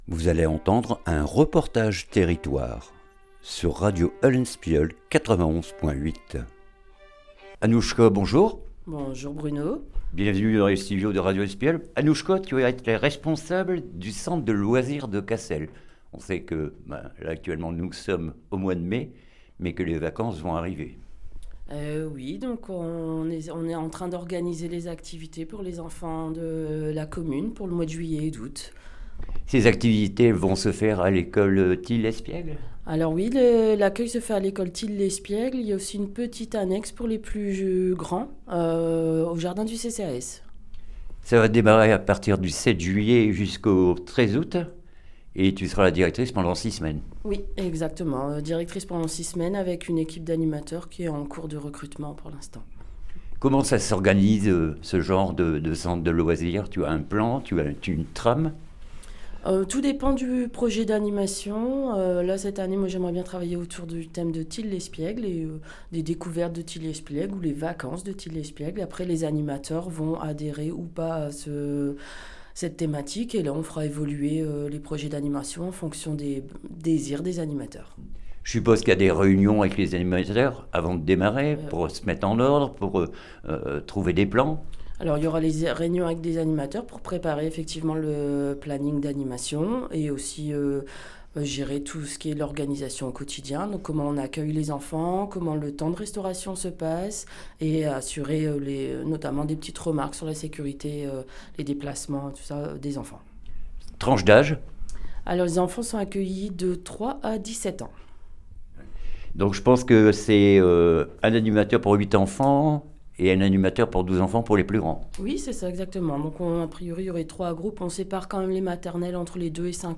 REPORTAGE TERRITOIRE CENTRE DE LOISIRS CASSEL